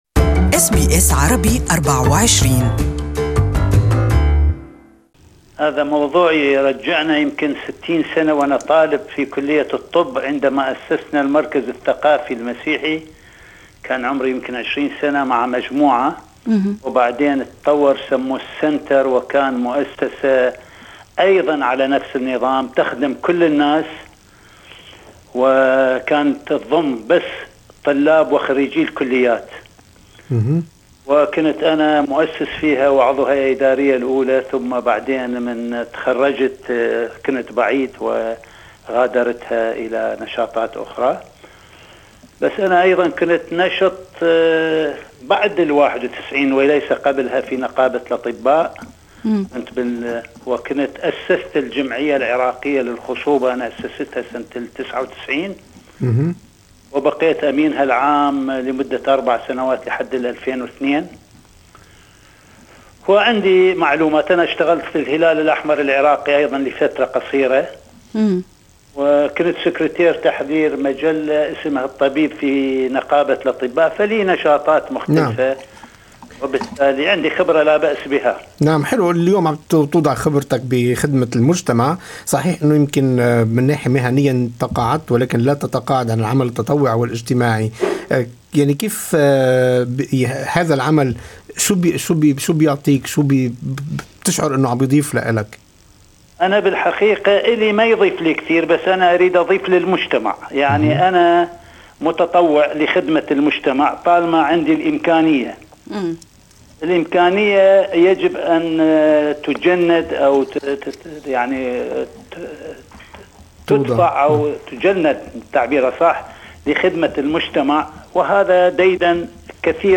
استمعوا الى اللقاء كاملا في التدوينة الصوتية أعلى الصفحة.